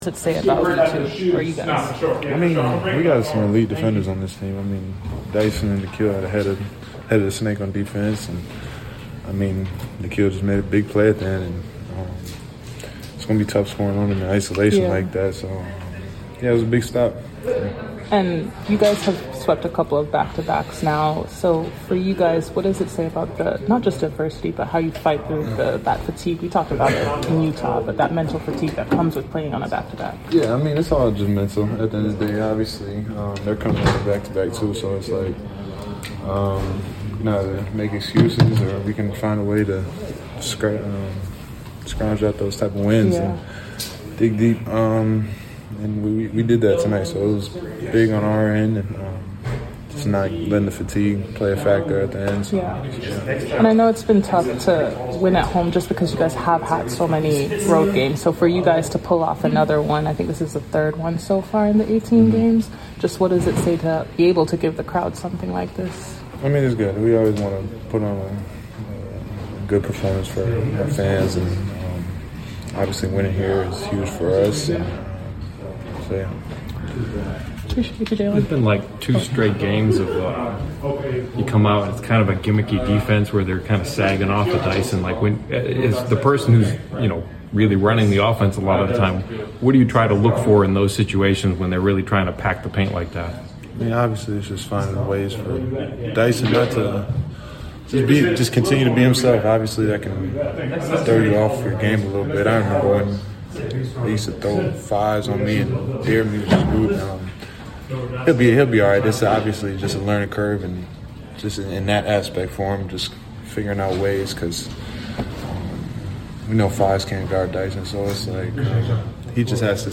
Atlanta Hawks Forward Jalen Johnson Postgame Interview after defeating the Charlotte Hornets at State Farm Arena.